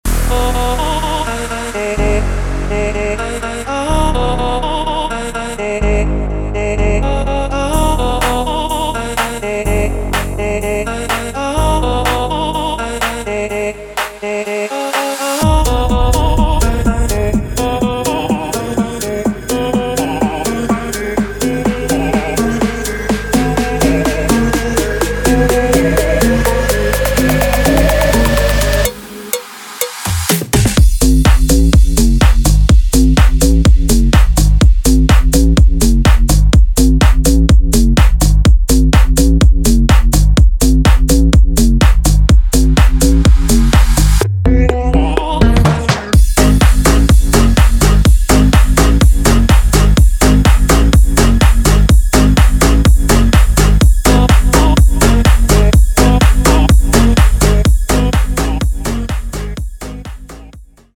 Slap House